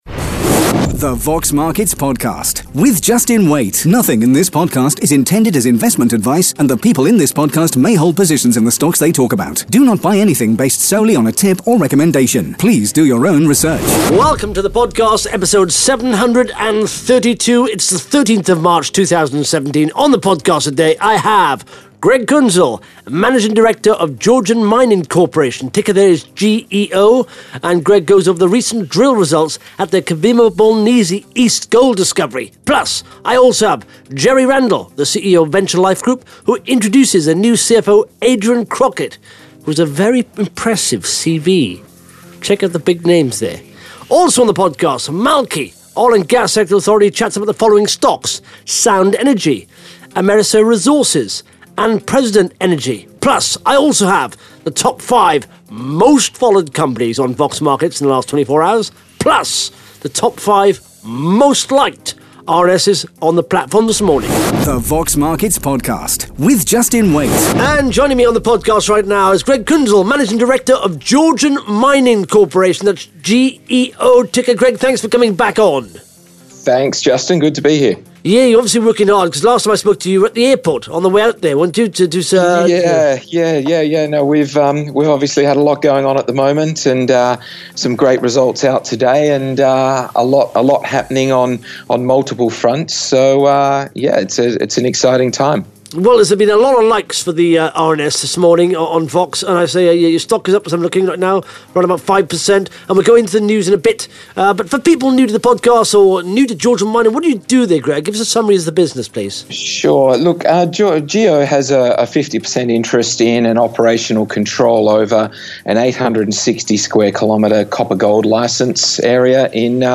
(Interview starts at 1 minute 4 seconds)